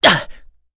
Ezekiel-Vox_Damage_02_kr.wav